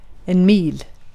Ääntäminen
UK : IPA : /maɪ̯l/ US : IPA : /maɪ̯l/